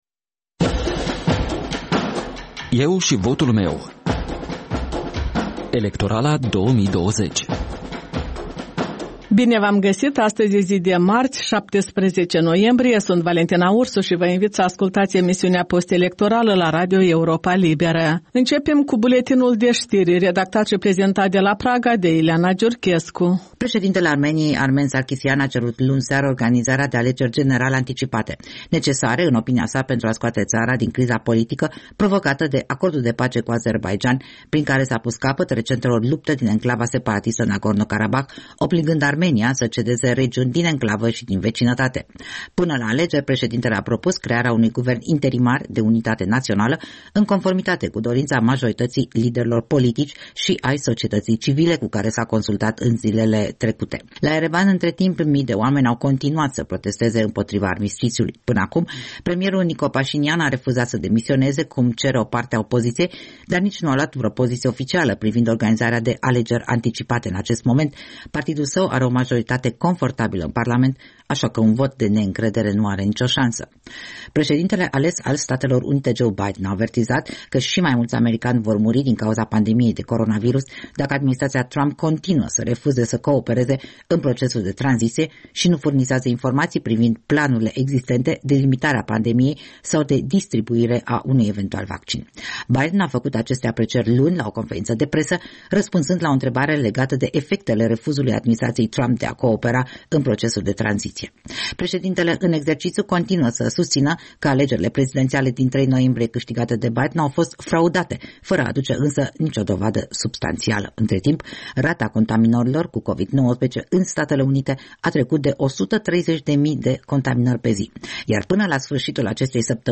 De luni până vineri, de la ora 13.00, radio Europa Liberă prezintă interviuri cu candidații în alegerile prezidențiale din 15 noiembrie, discuții cu analiști și formatori de opinie, vocea străzii și cea a diasporei.